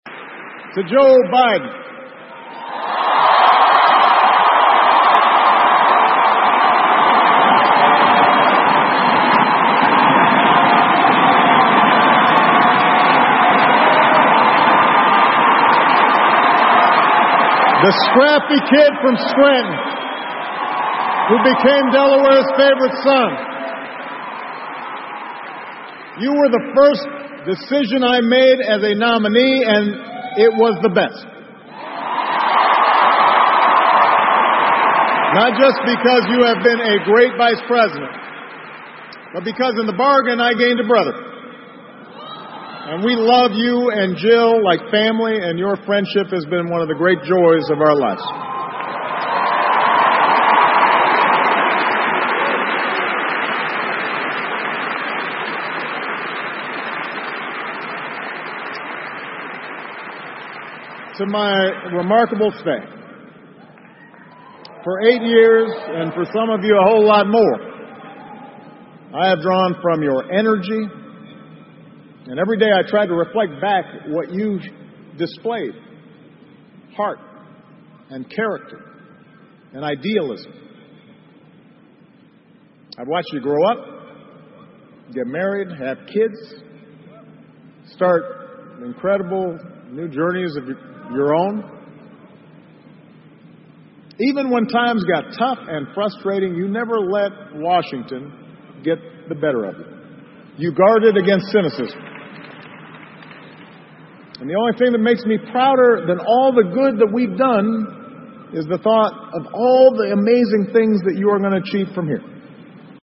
奥巴马每周电视讲话：美国总统奥巴马告别演讲(21) 听力文件下载—在线英语听力室